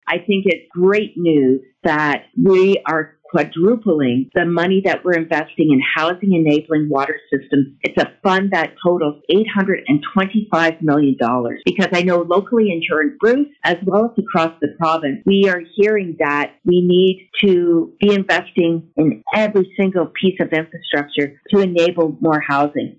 myFM caught up with Ontario Minister of Agriculture, Food and Rural Affairs, Lisa Thompson who told us her thoughts on the budget announcement.